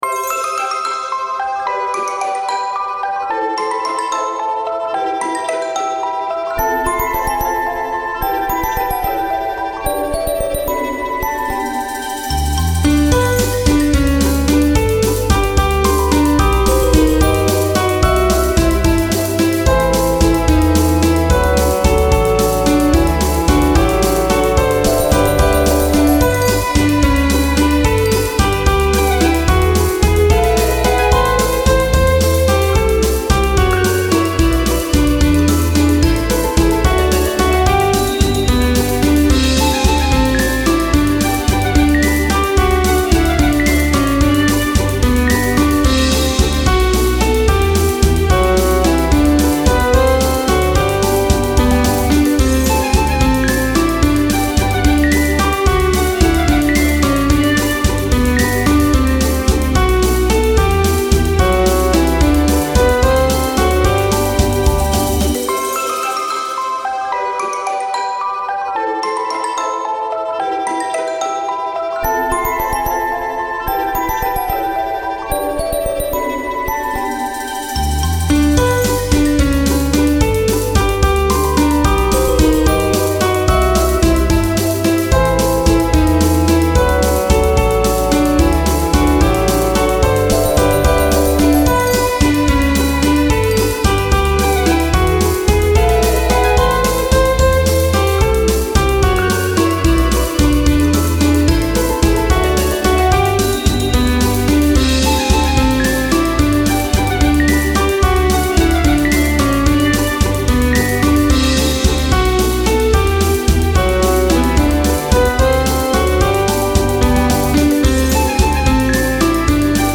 • Категория: Детские песни
караоке
минусовка
вальс